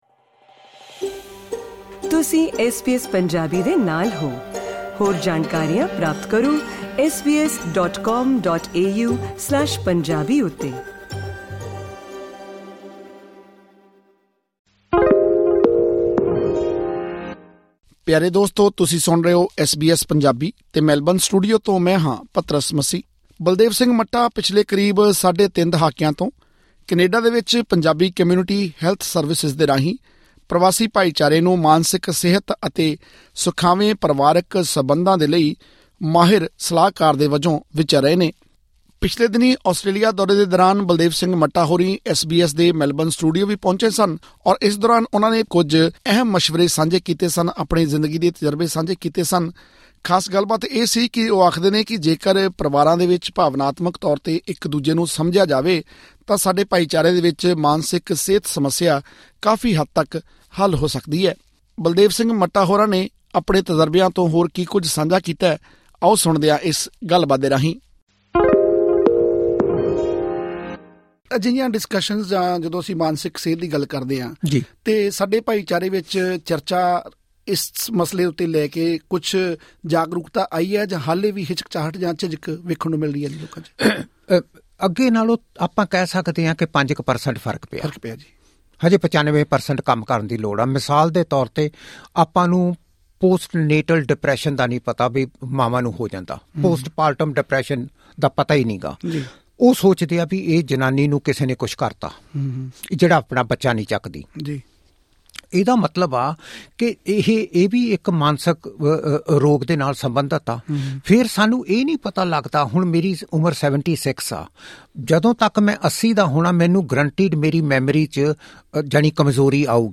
ਐਸ ਬੀ ਐਸ ਮੈਲਬਰਨ ਸਟੂਡੀਓ ਵਿਖੇ ਗੱਲਬਾਤ ਕਰਦੇ ਹੋਏ